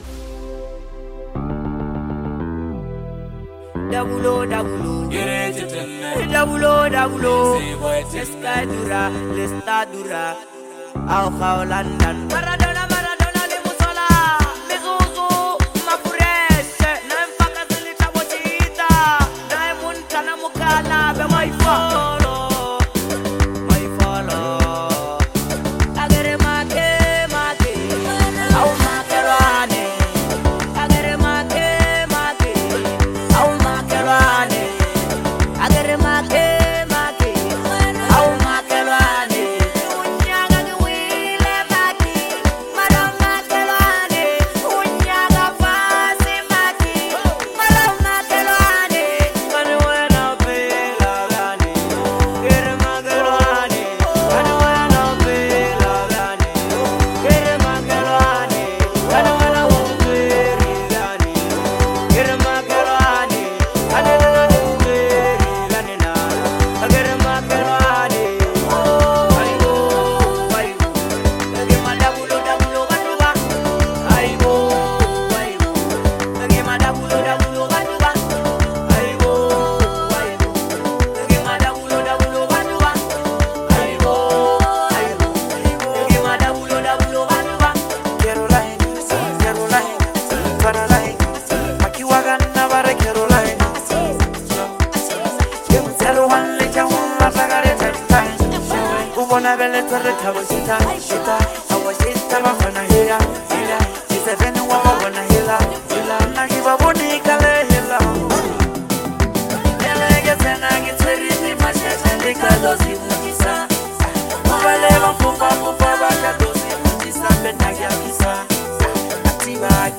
nostalgic and high spirited anthem